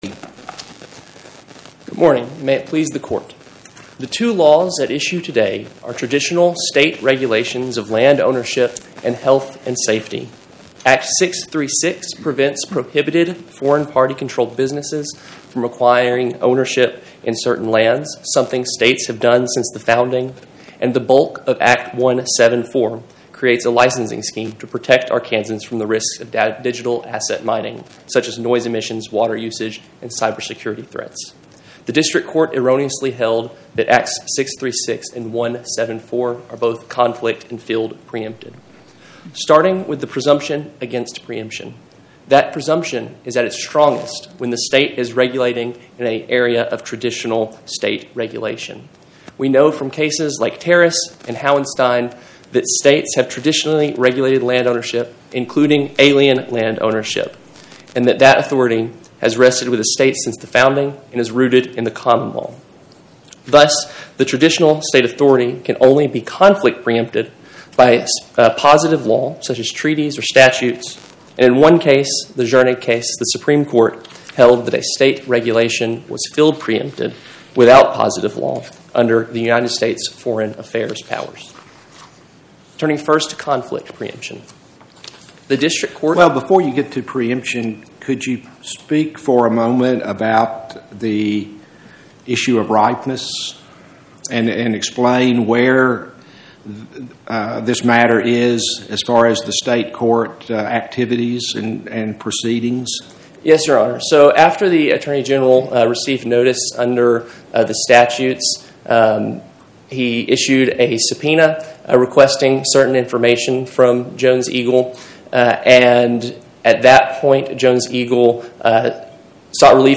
My Sentiment & Notes 25-1047: Jones Eagle LLC vs Wes Ward Podcast: Oral Arguments from the Eighth Circuit U.S. Court of Appeals Published On: Wed Jan 14 2026 Description: Oral argument argued before the Eighth Circuit U.S. Court of Appeals on or about 01/14/2026